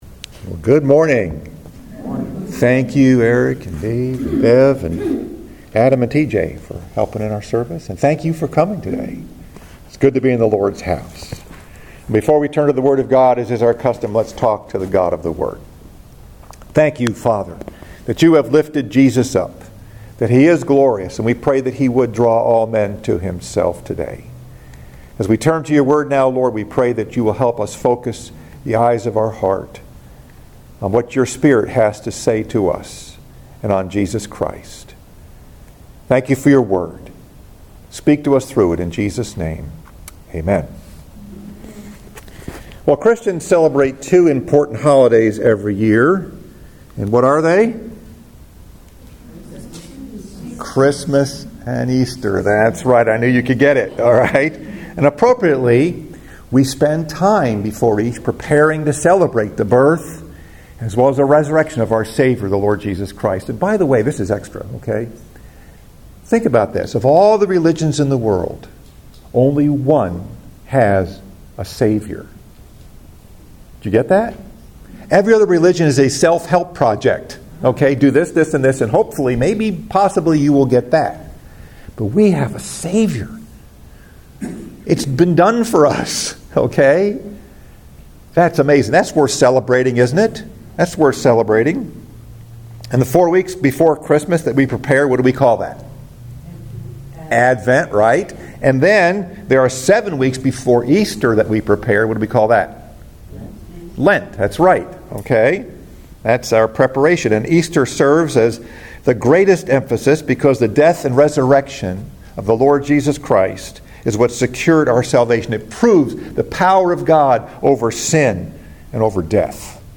Message: “Up, Up and Away!” Scripture: Acts 1: 1-11